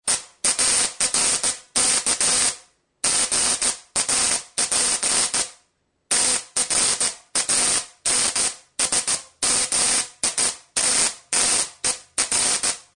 Präsentation Löschfunkenstation
spark-gap-96.mp3